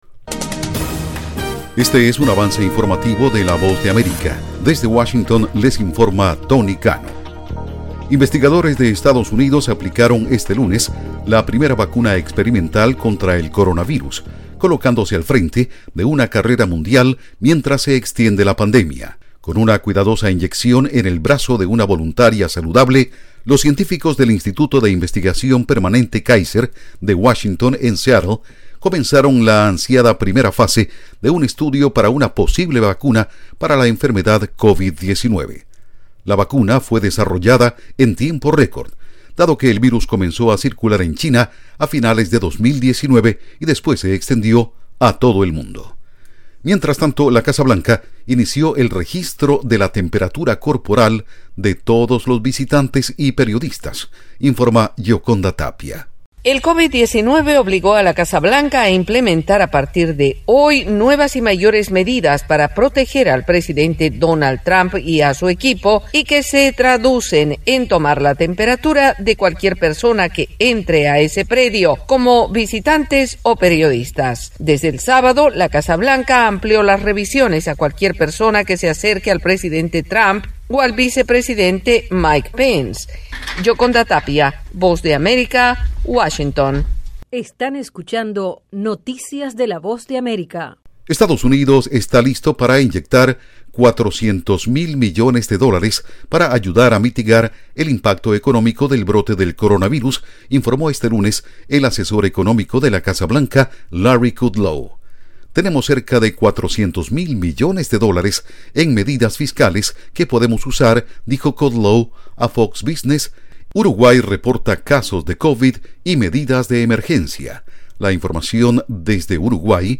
Este es el avance informativo de las dos de la tarde